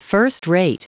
Prononciation du mot first-rate en anglais (fichier audio)
Prononciation du mot : first-rate